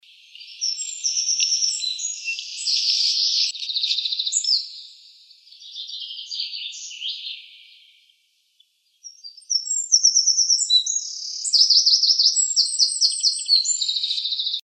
Troglodytes troglodytes
Nome em Inglês: Eurasian Wren
Fase da vida: Adulto
Localidade ou área protegida: Botanic Garden de Cambridge
Certeza: Observado, Gravado Vocal
Wren.MP3